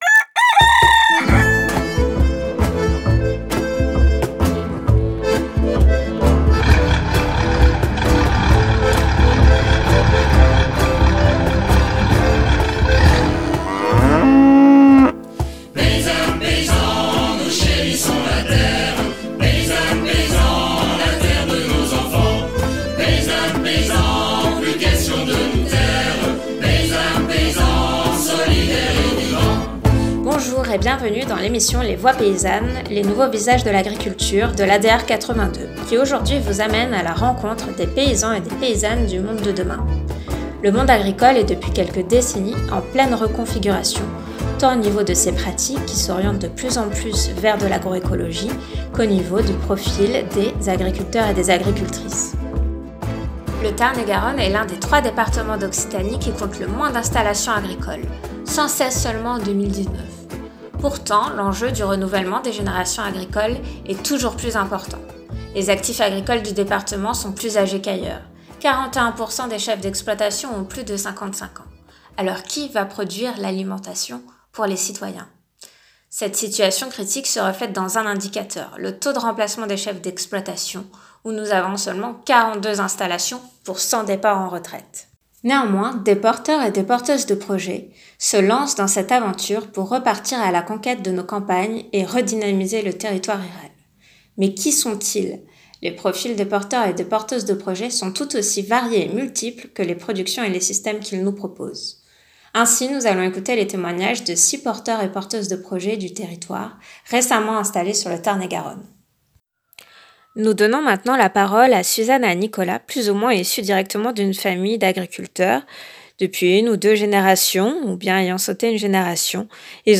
Émission sur les nouveaux porteurs de projets, issus du milieu agricole et hors cadre familial.